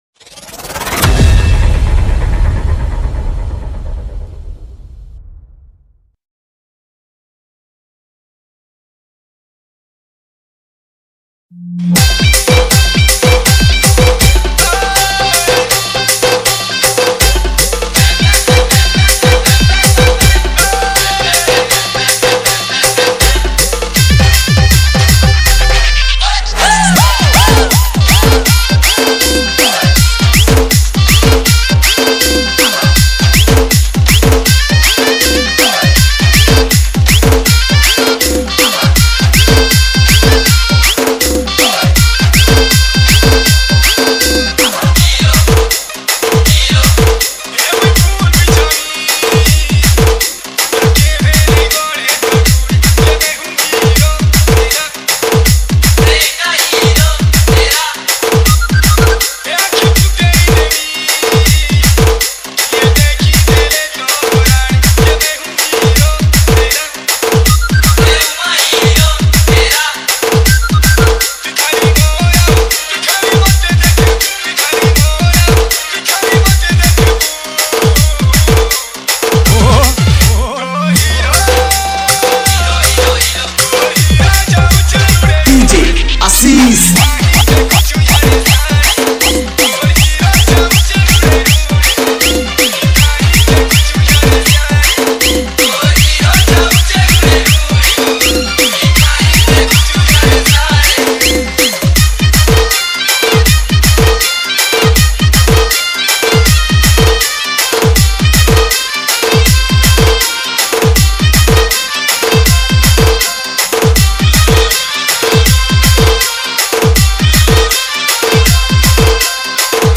SAMBALPURI ROMANTIC DJ REMIX